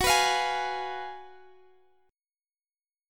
Listen to F#M7sus2sus4 strummed